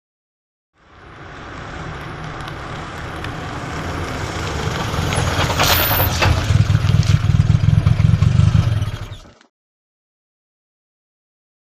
Automobile; In / Stop / Off; Audi 80l Approach Up Gears And Stop At Mic. Short Idling And Switch Off. Slight Curbside Grit.